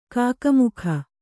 ♪ kāka mukha